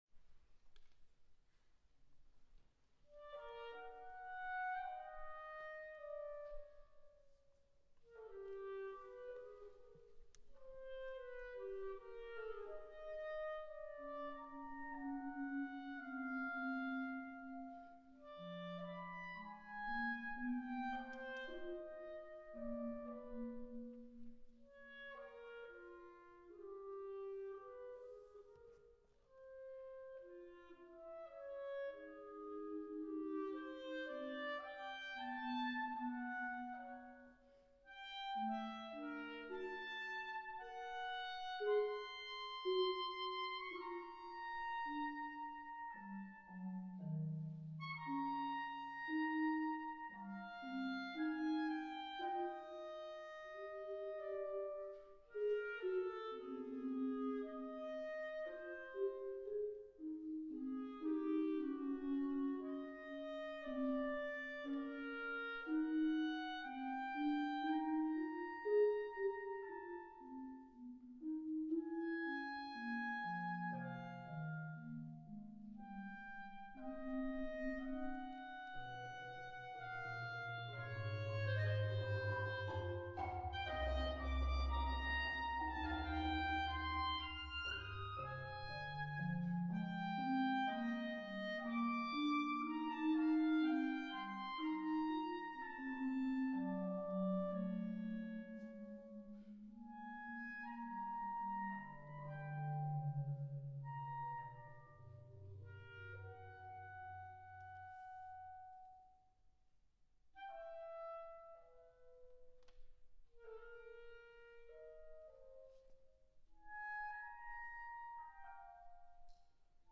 Genre: Duet for Clarinet & Marimba/Vibraphone + CD
B-flat Clarinet
Marimba (4.3-octave)
CD Accompaniment